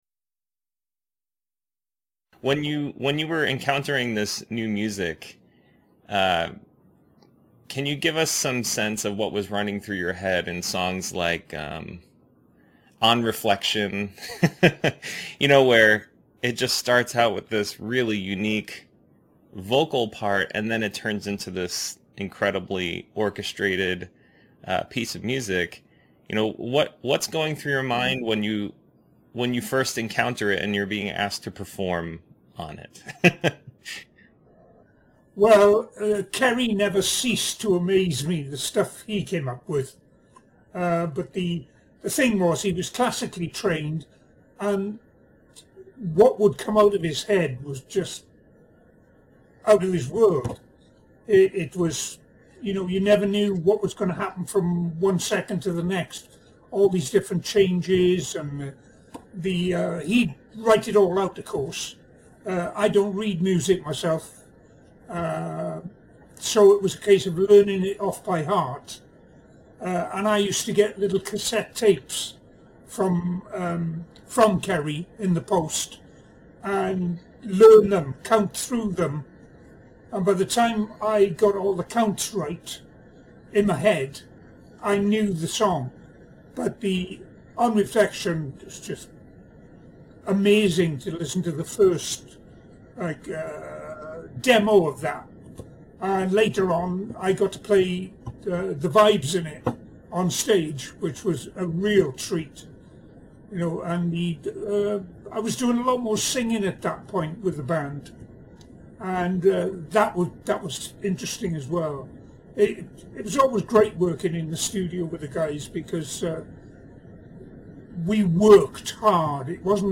In einem wunderbaren, berührenden Interview spricht John Weathers – Drummer von GG seit 1972 – über dieses bedeutende Album, über die Musiker, die alle virtuose Multiinstrumentalisten sind.